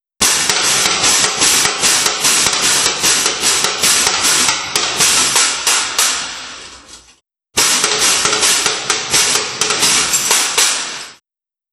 Nagrania dźwiękowe gry na diabelskich skrzypcach.
23.DIABELSKIE-SKRZYPCE.wav